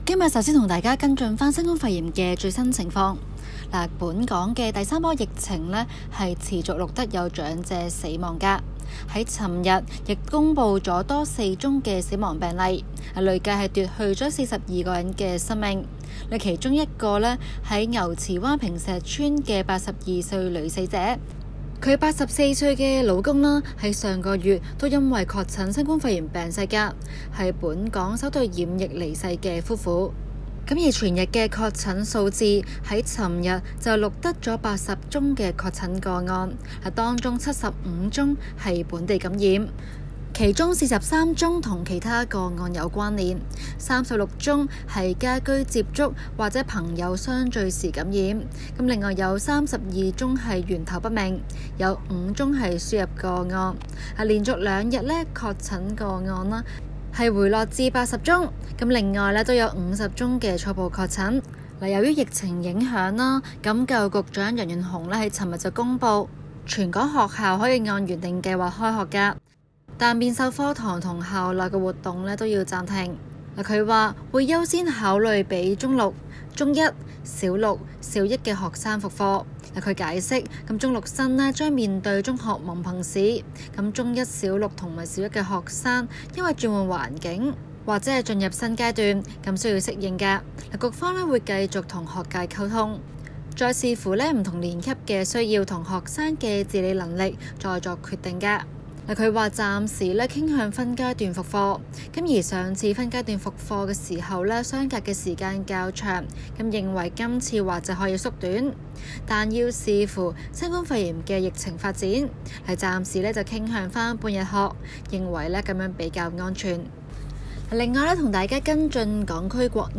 今期【中港快訊 】環節報道香港疫情最新發展，及相繼有被取消參選資格的立法會議員辭去其他公職。